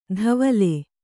♪ dhavale